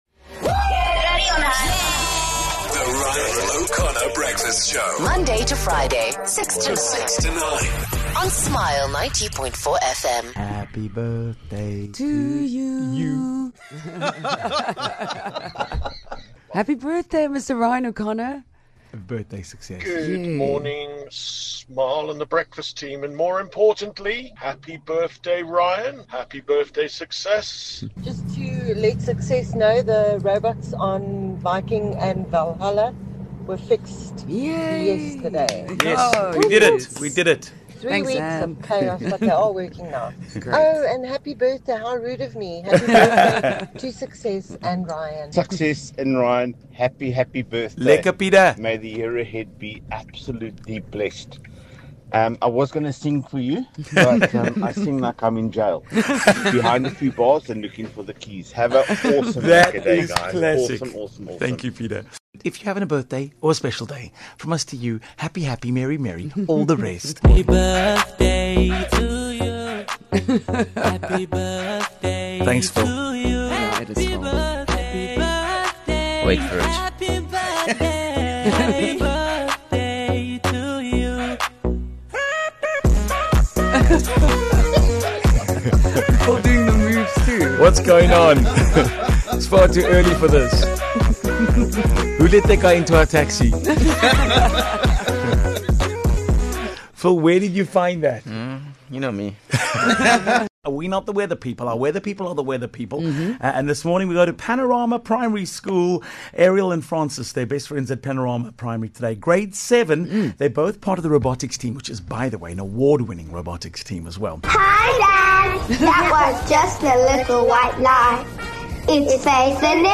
It's the only breakfast radio show with half the team sharing a birthday.
Even Eddie Murphy, Snoop Dogg, and Barack Obama wished them this morning (haters will say it's A.I.)